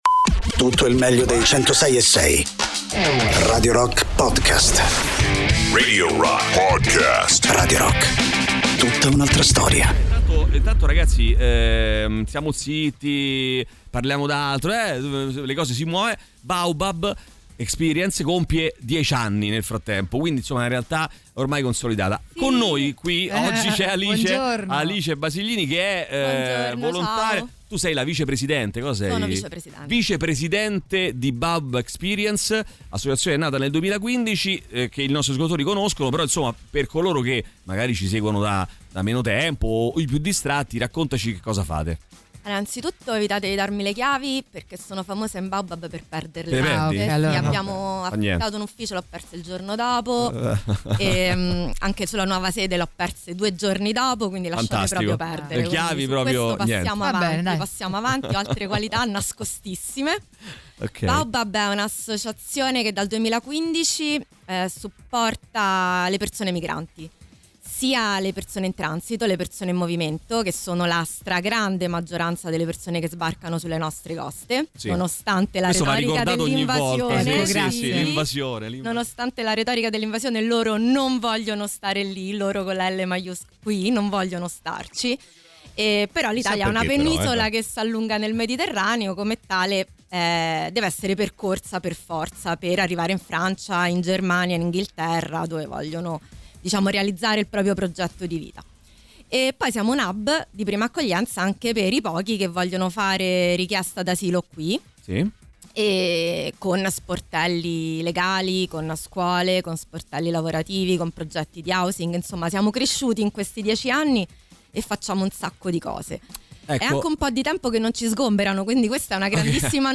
Interviste